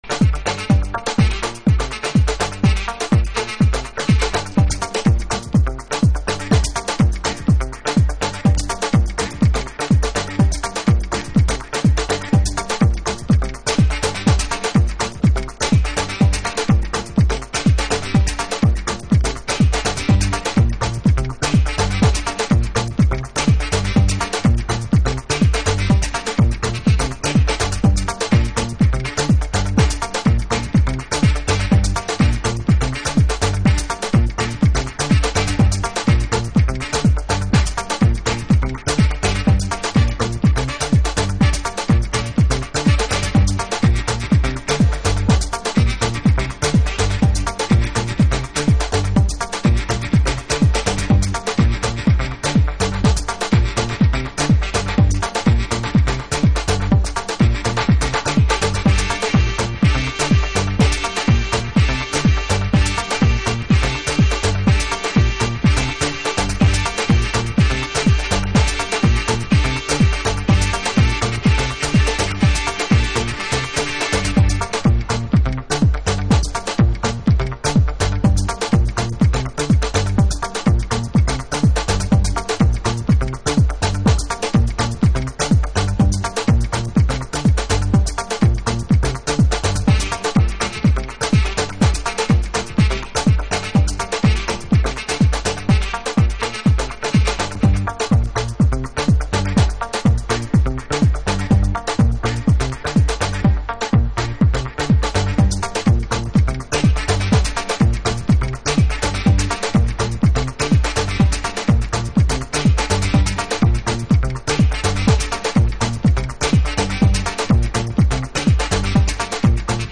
シンプルなトラック数で威力バツグンの鳴りを聴かせてくれるマシーンアフロリズムは健在。